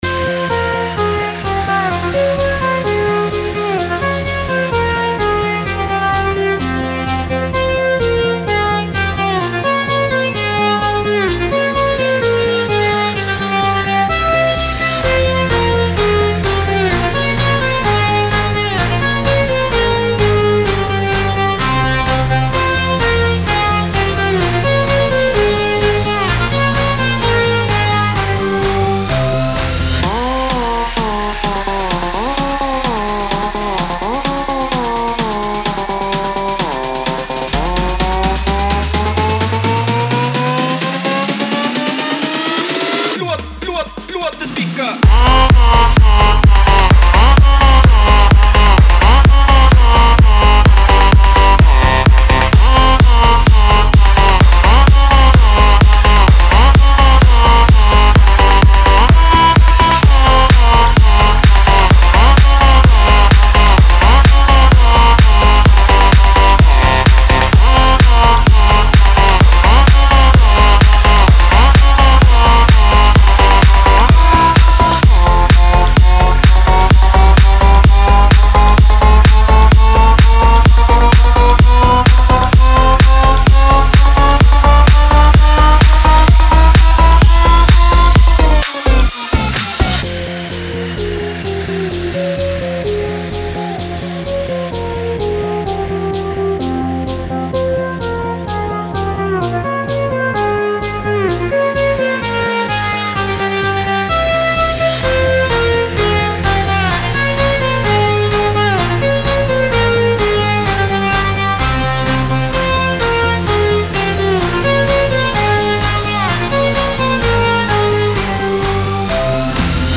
It'sa track which could be put into genre "Melbourne Bounce" and its called "Blew up the Speaker!". It's a typical festival tune.
Young people who love festival sounds.